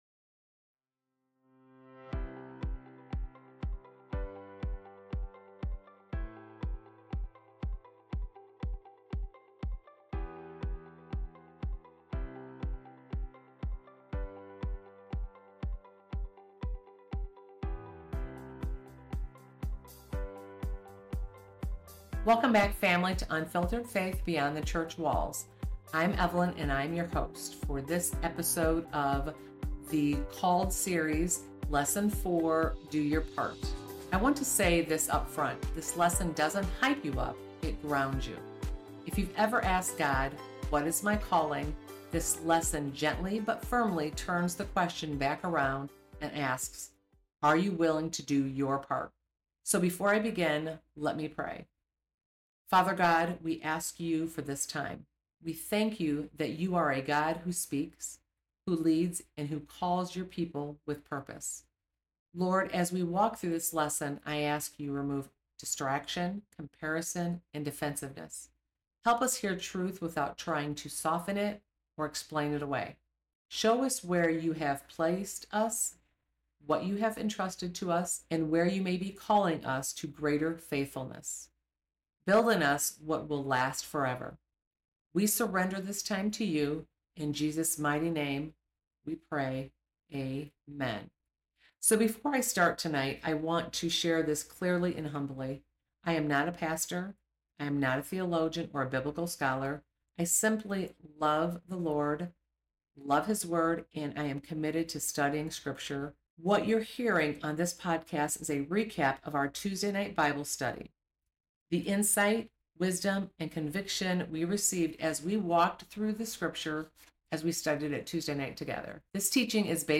This solo episode walks through the key scriptures, insights, and takeaways from our recent study, unpacking God’s Word in a practical, relatable way.
We are not pastors or biblical scholars—just women who love the Lord and want to share the wisdom and insight God gives us through study, prayer, and lived experience.